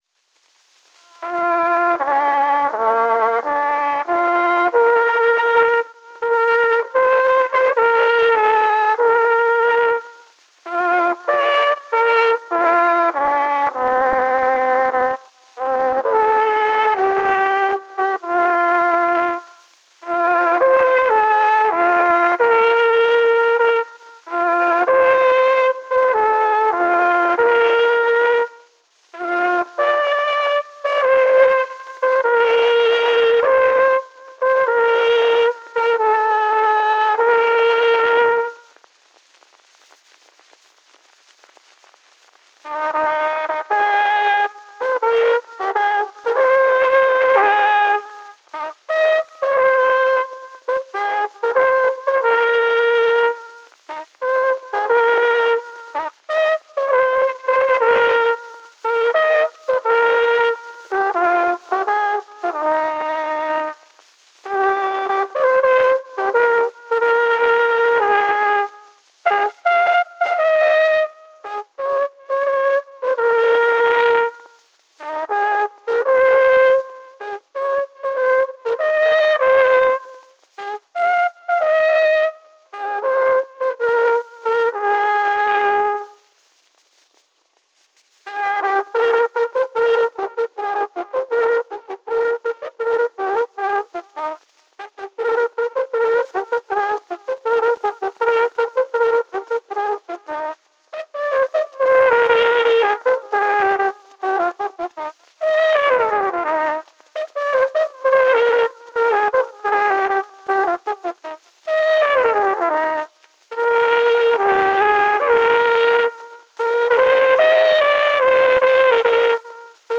[Tre melodier spillet på kornet]
Tre meloder spillet på kornet. Optagelsen afsluttes med replikken: "Olaf Poulsen længe leve", efterfulgt af tre hurra-råb.